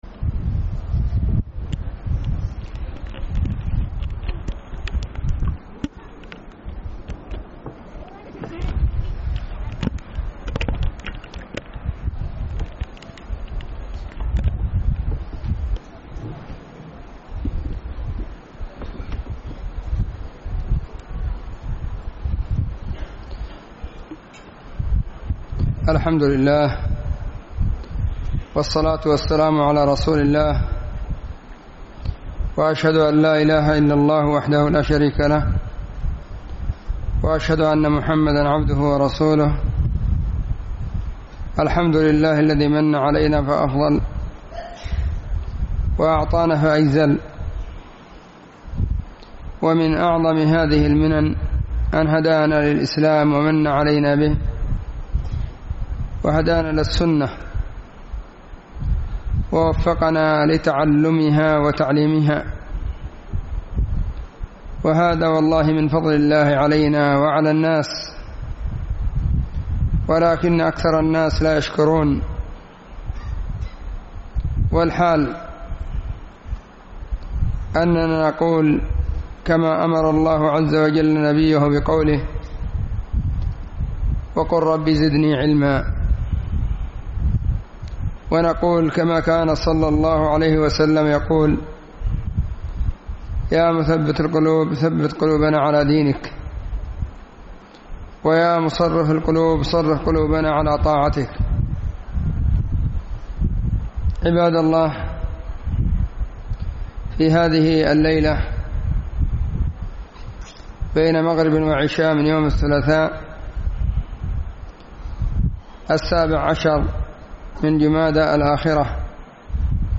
الحمد لله والصلاة والسلام على رسول الله أما بعد:🎙 فهذه محاضرة – في مسجد – ابن باز – في الديس الشرقية – حضرموت.
لا_تغضب_محاضرة_في_مسجد_ابن_باز_بالديس.mp3